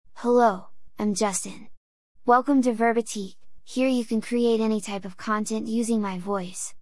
JustinMale US English AI voice
Justin is a male AI voice for US English.
Voice sample
Listen to Justin's male US English voice.
Male